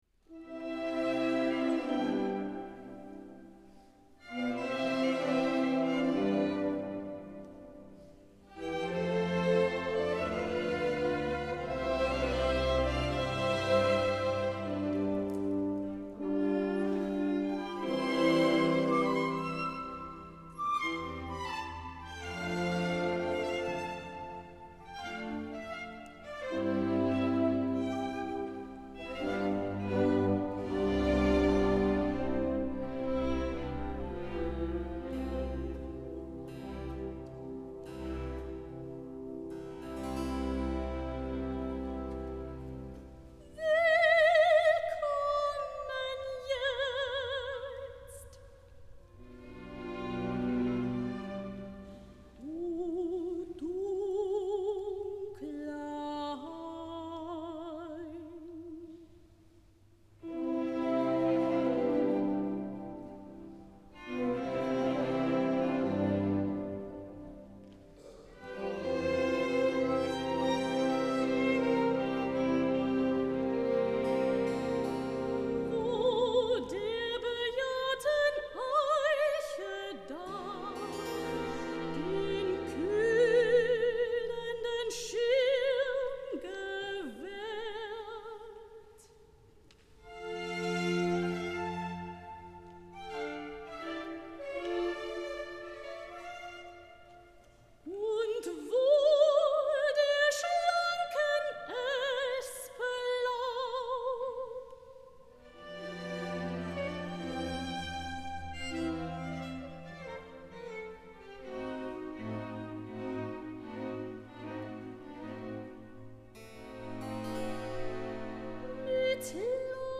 Sopran
Hier habe ich einige Konzertmitschnitte für Sie ausgewählt
Bach: Arie "Seele, deine Spezereien" (Osteraratorium)